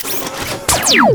larkanstungun.wav